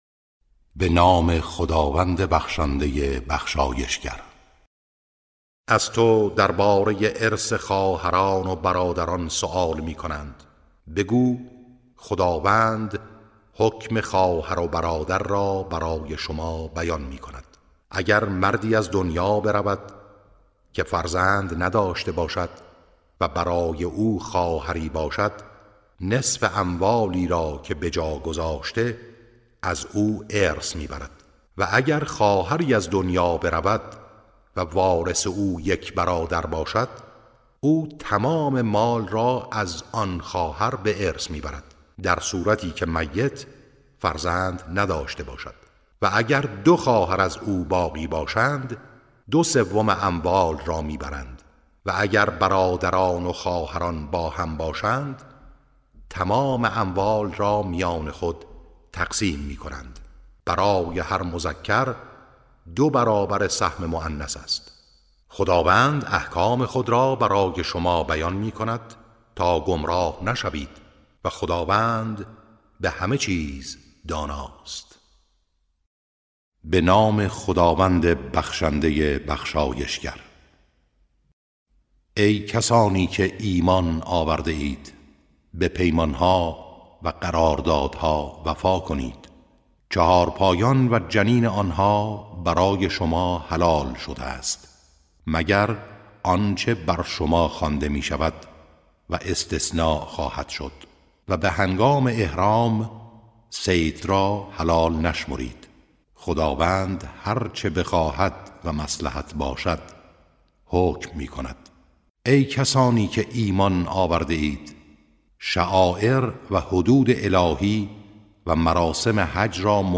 ترتیل صفحه ۱۰۶ سوره مبارکه نساء و مائده(جزء ششم)